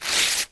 rustle.paper_Tear_9
cruble noise paper rip rustle scratch tear sound effect free sound royalty free Nature